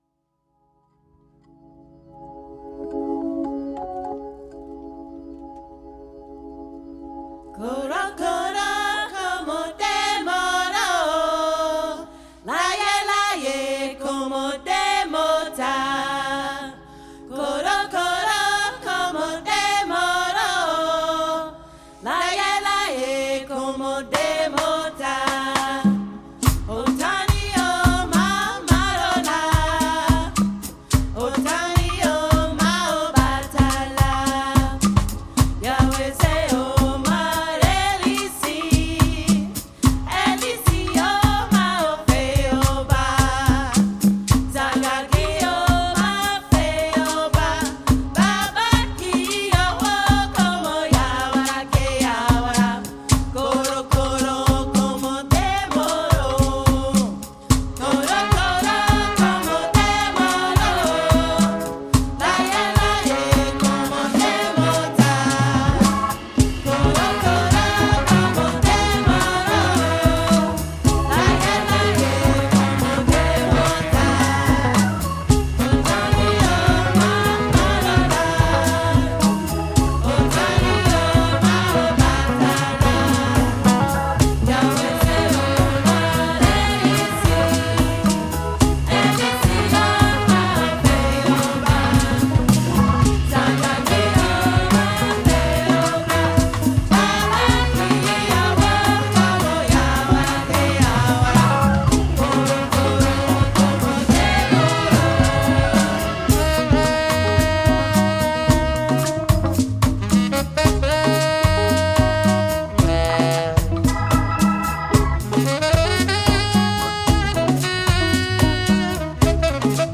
jazz-centric interpretations